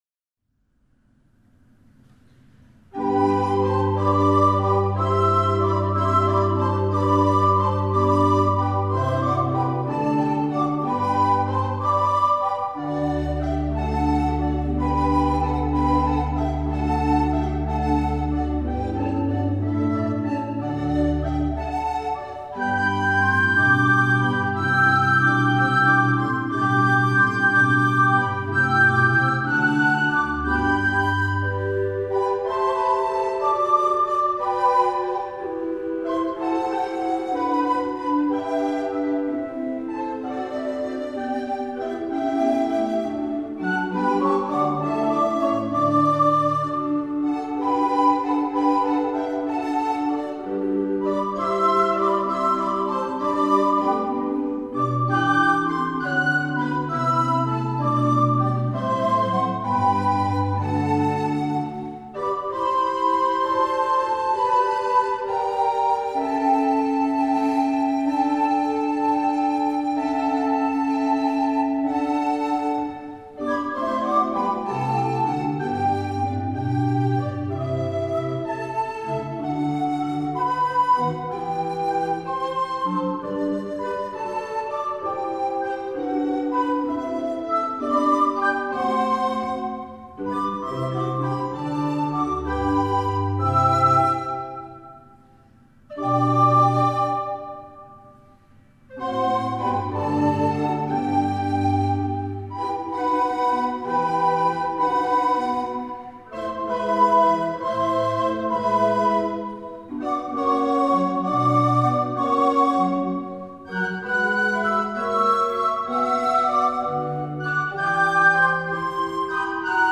Arcangelo Corelli (1653-1713) Pastorale
Blockflötenensemble Berlin-Neukölln
Orgel